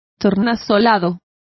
Complete with pronunciation of the translation of iridescent.